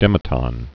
(dĕmĭ-tŏn)